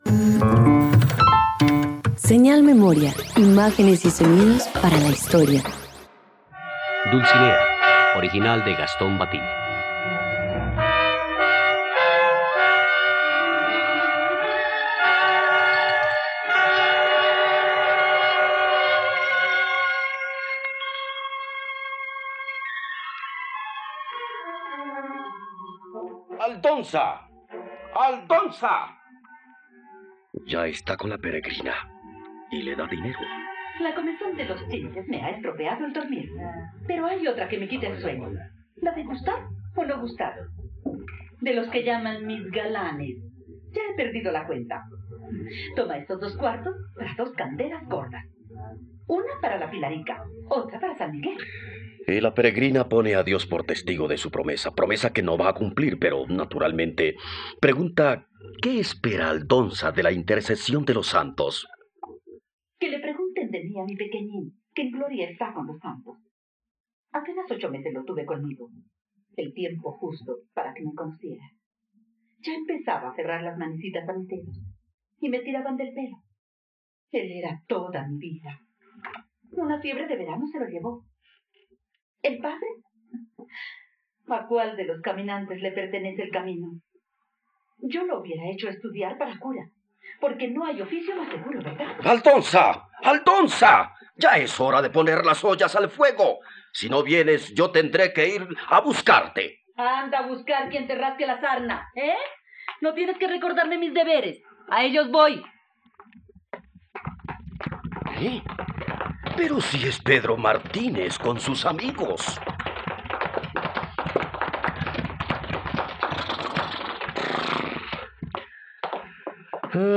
Dulcinea - Radioteatro dominical | RTVCPlay